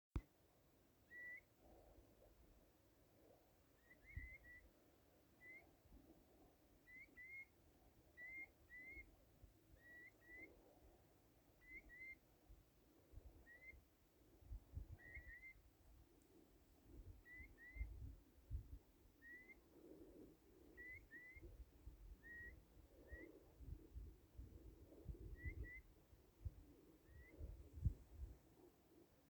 Birds -> Waders ->
Eurasian Curlew, Numenius arquata
Count2
StatusVoice, calls heard